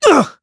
KaselB-Vox_Damage_kr_03.wav